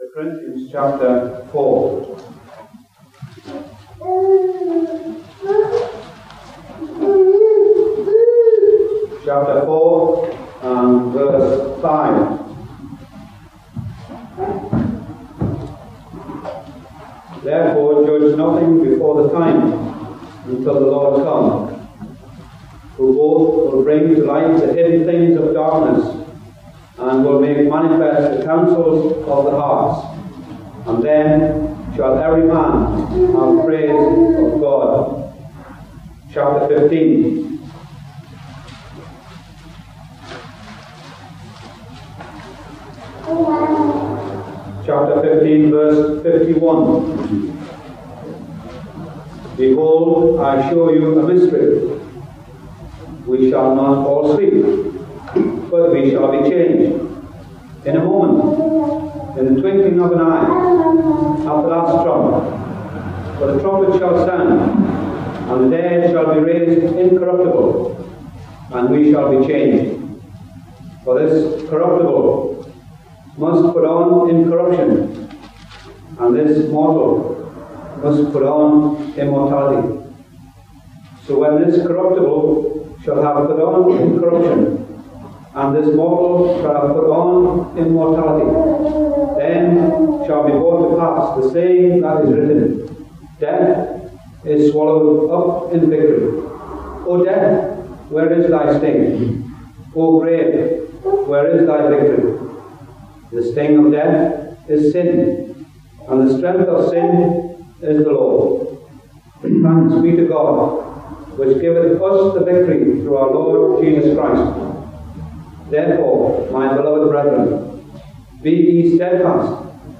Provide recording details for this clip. View our Christian Ministry downloadable mp3 recordings from lectures and conferences across the UK over the last 60 years.